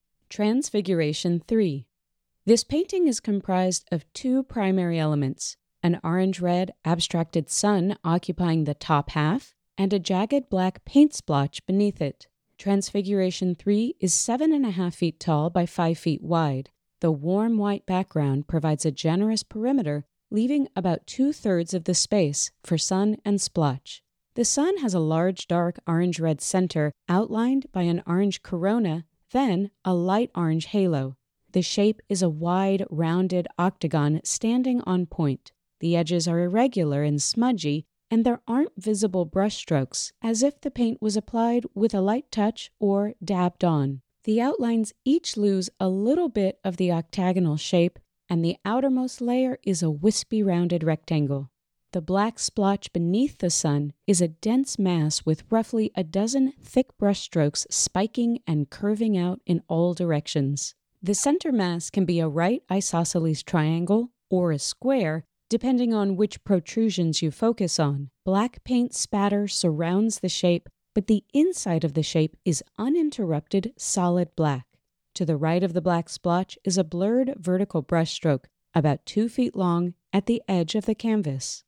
Audio Description (01:22)